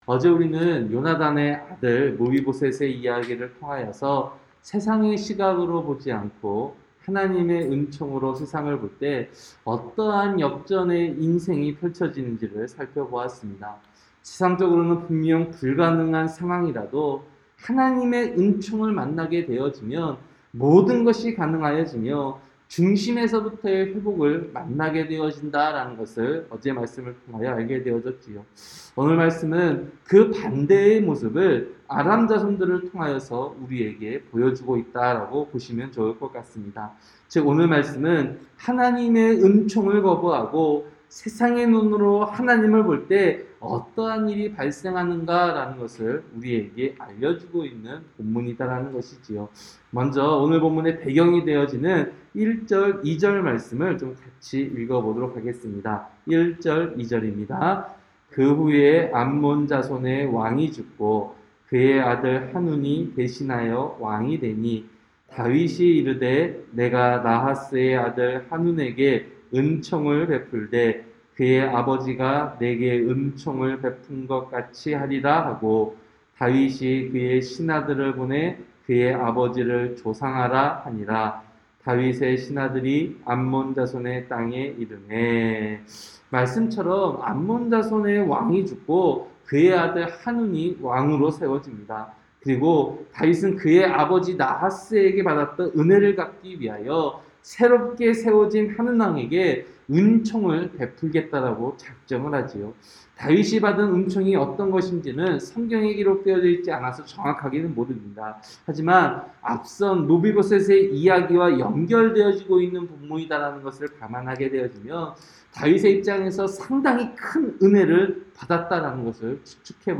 새벽설교-사무엘하 10장